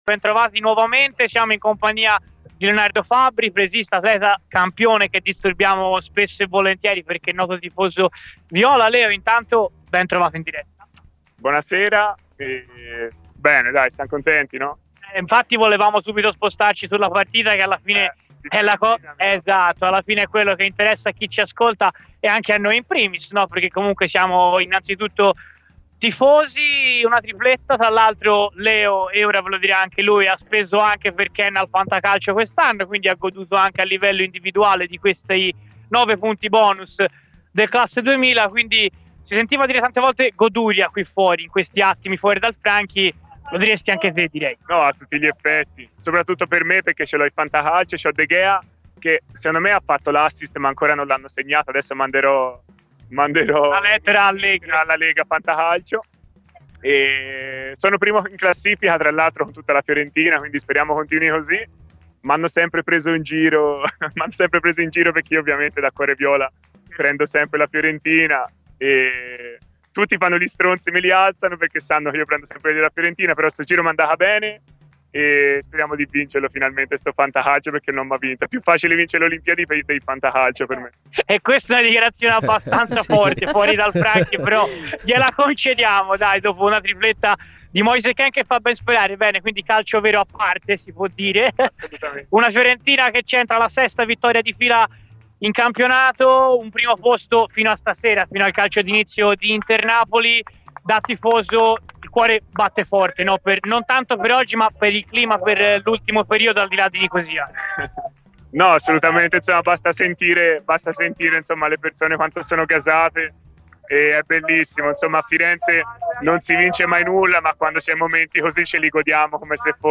Nel post partita di Fiorentina-Hellas Verona ha parlato in esclusiva ai microfoni di Radio FirenzeViola l'atleta olimpico di getto del peso e grande tifoso viola Leonardo Fabbri: "È una goduria, per me doppia perché ho Kean e De Gea al fantacalcio che mi hanno portato al primo posto in classifica.